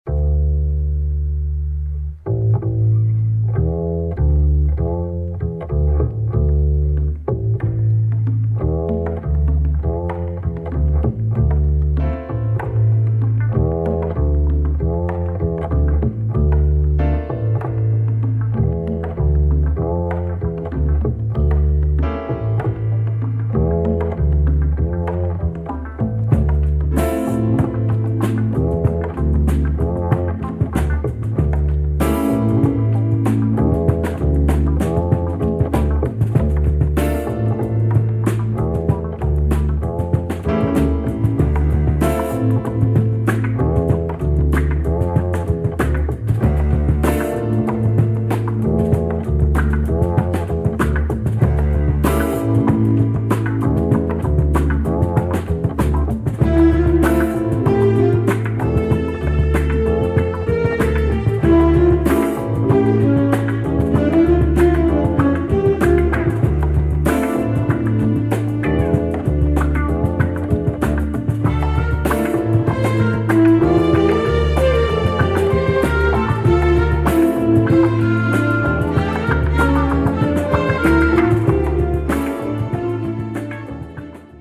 a deep slow groove
with an irresistible wooden bass LOOP intro.